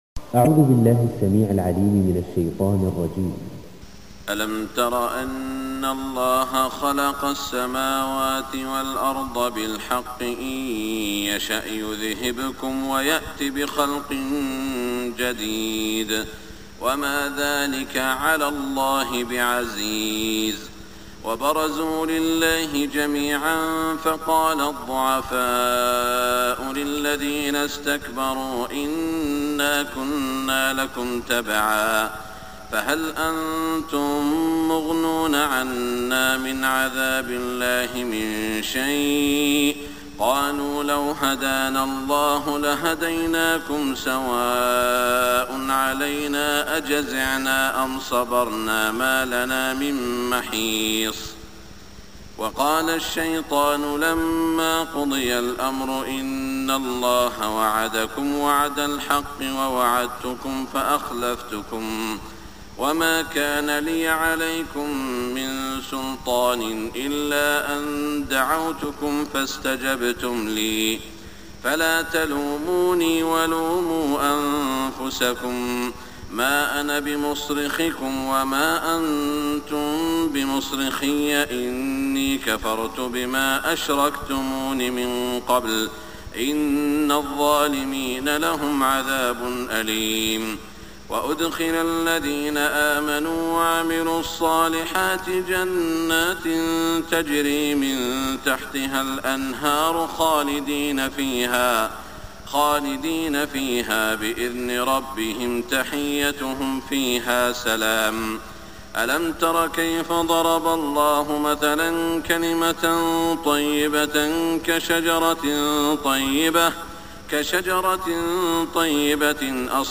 صلاة الفجر 1425 من سورة إبراهيم > 1425 🕋 > الفروض - تلاوات الحرمين